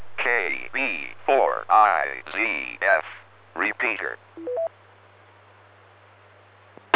Hear the Repeater Initial ID
repeater.wav